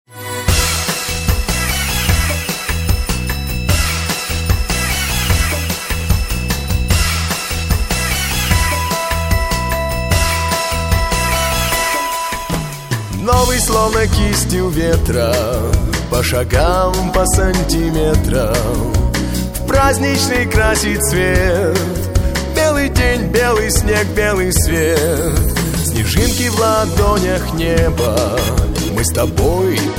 Русские песни и красивая музыка для души